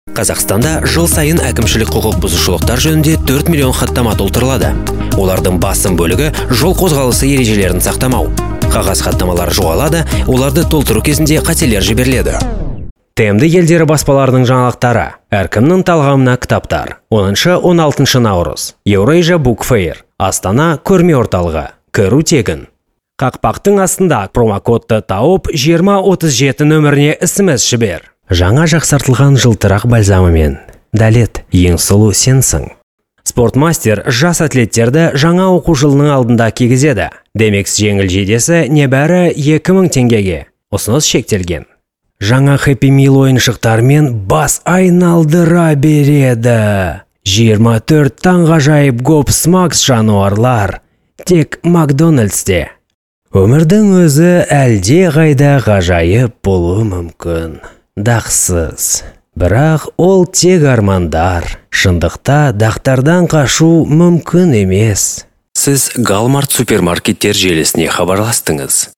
Муж, Другая
микрофон Antelope Audio Edge Solo внешняя звуковая карта M-Audio M-Track 2X2M